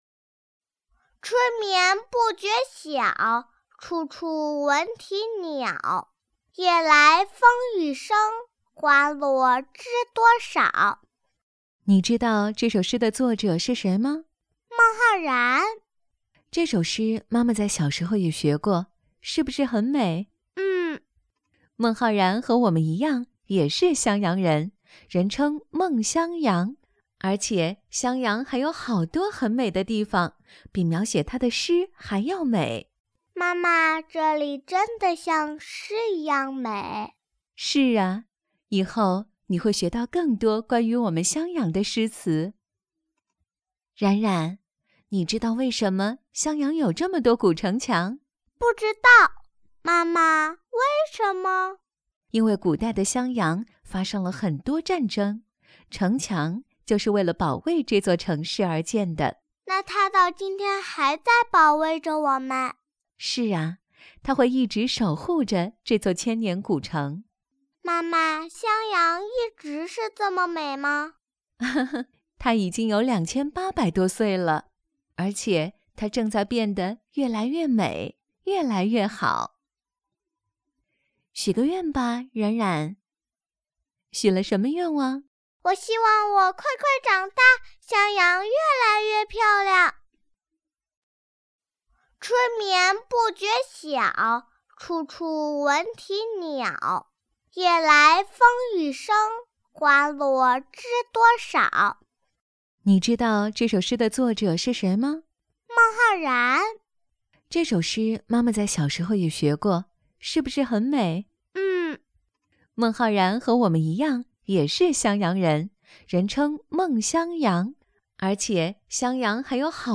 职业配音员全职配音员大气
• 女S12 国语 女声 童声 女童和妈妈对话 女声合-襄阳3D影院 亲切甜美|素人